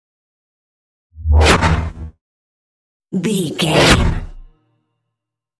Scifi whoosh pass by x2
Sound Effects
futuristic
pass by